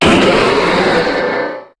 Cri de Méga-Sharpedo dans Pokémon HOME.
Cri_0319_Méga_HOME.ogg